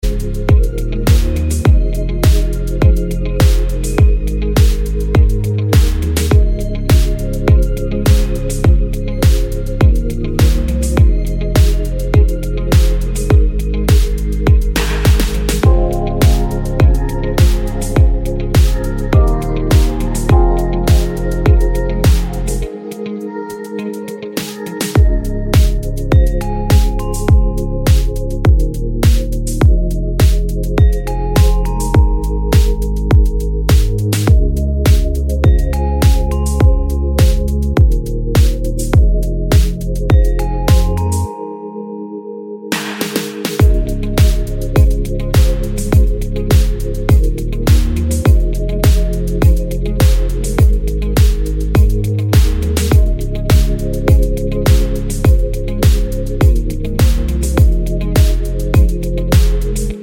no Backing Vocals Pop (2010s) 2:52 Buy £1.50